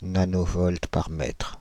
Prononciation
Prononciation France (Île-de-France): IPA: /na.nɔ.vɔlt paʁ mɛtʁ/ Le mot recherché trouvé avec ces langues de source: français Traduction Contexte Substantifs 1.